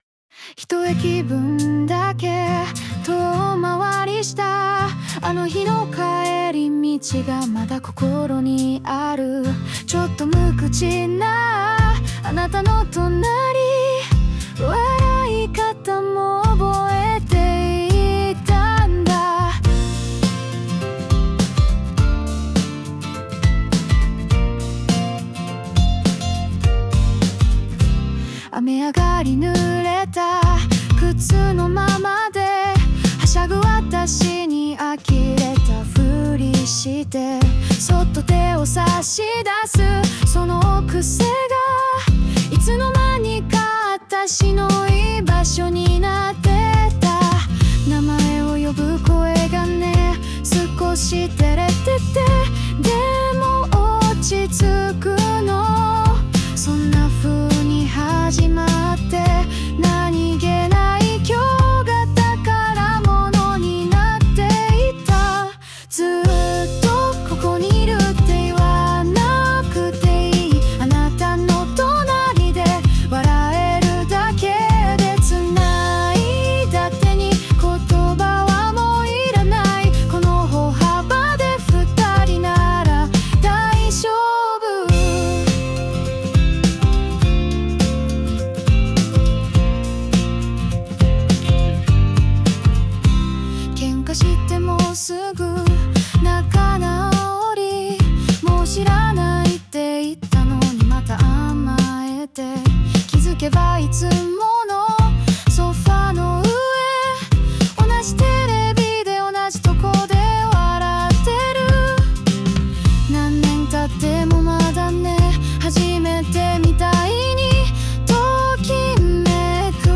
著作権フリーオリジナルBGMです。
女性ボーカル（邦楽・日本語）曲です。
ありきたりな言葉では表せない想いを、少しキュートであたたかなメロディにのせて届けられたら嬉しいです。